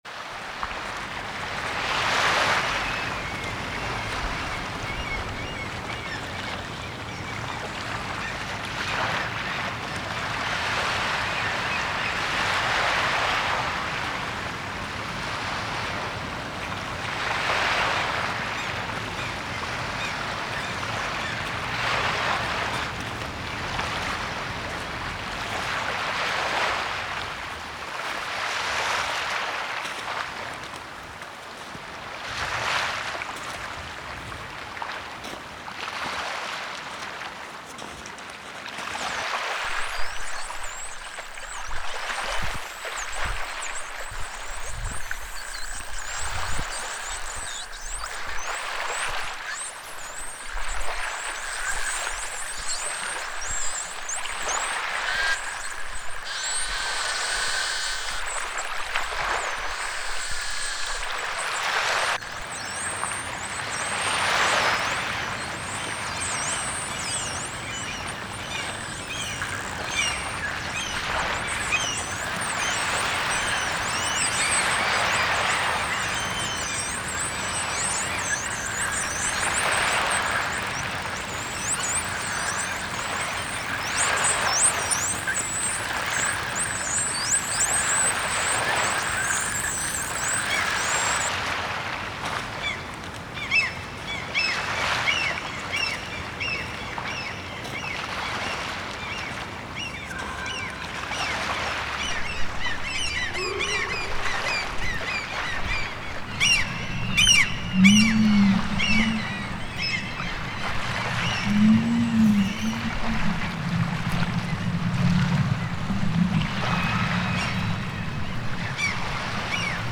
المشي على الرمال على شاطئ استوائي حافي القدمين مع الحيتان والدلافين وطيور النورس
ASMR مقاطع صوتية غامرة - رسوم متحركة مرضية للنوم والاسترخاء والقلق والإجهاد،